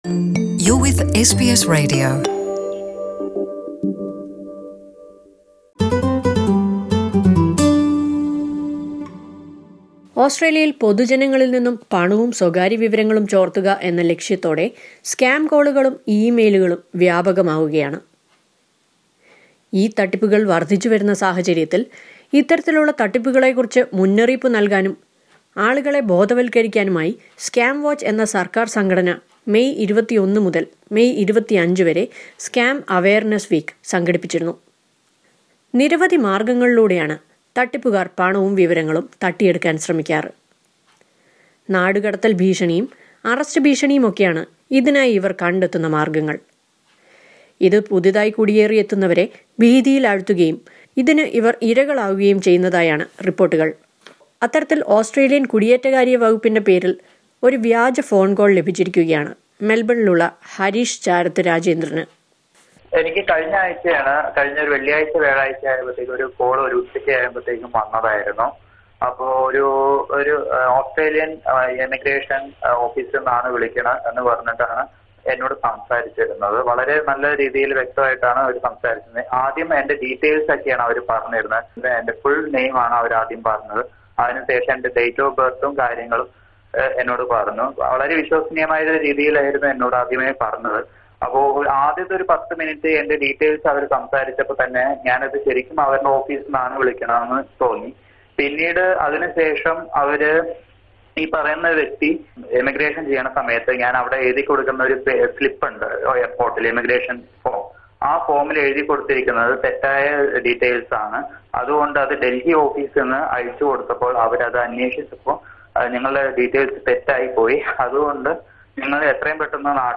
ഇതേക്കുറിച്ച് ഒരു റിപ്പോർട്ട് കേൾക്കാം മുകളിലെ പ്ലേയറിൽ നിന്ന്..